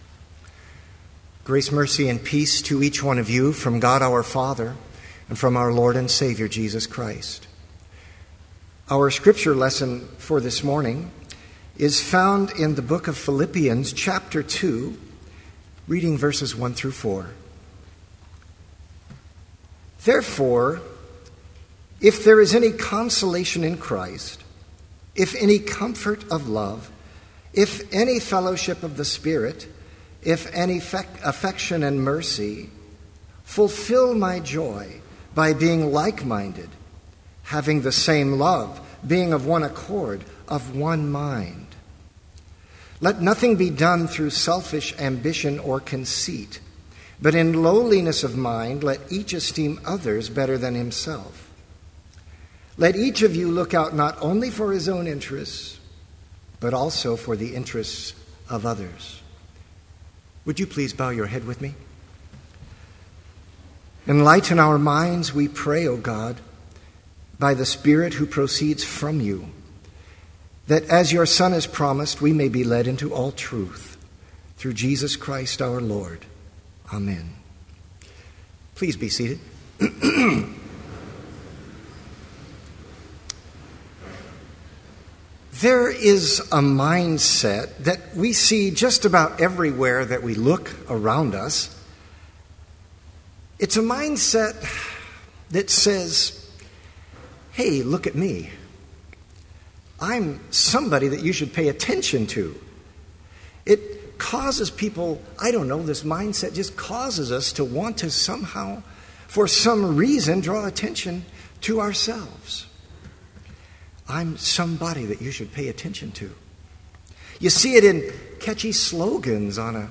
Complete service audio for Chapel - October 5, 2012